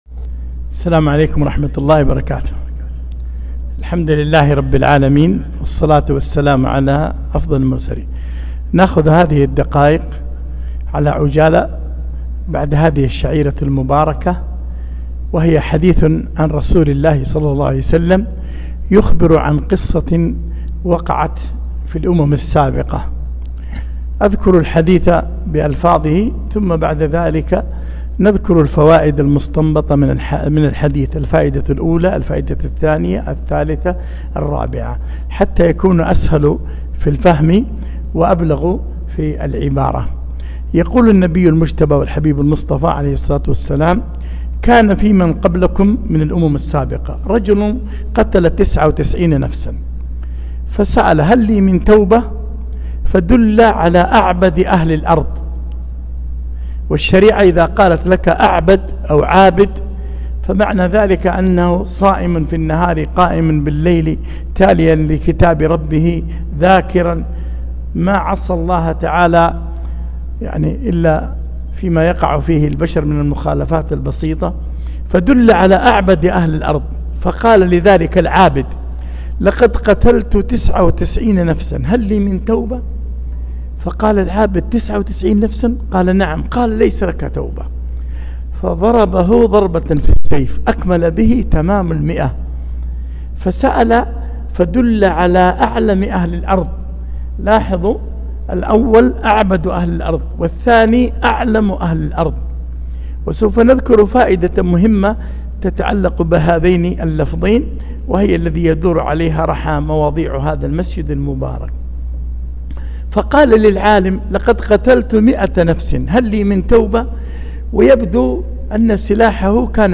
محاضرة - وقفات مع الرجل الذي قتل تسعة وتسعين نفسًا